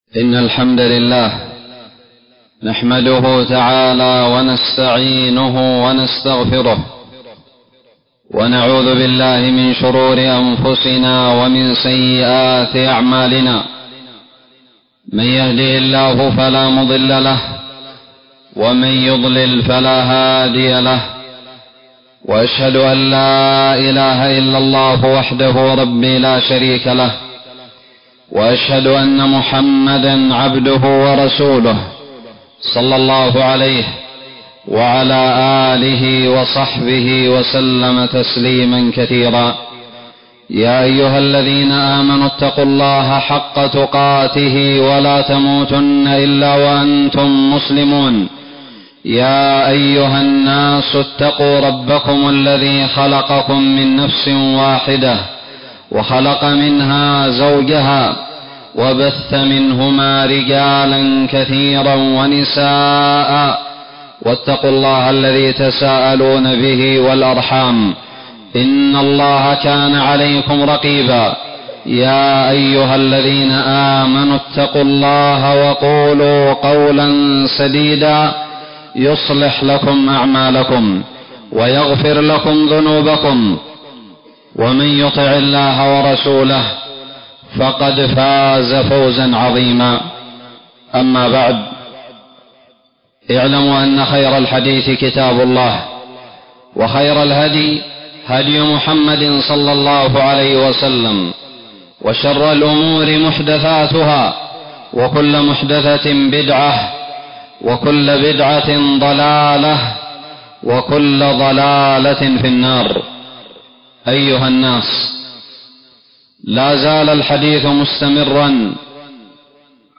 خطب الجمعة
ألقيت بدار الحديث السلفية للعلوم الشرعية بالضالع في 29 جمادى الأولى 1441هــ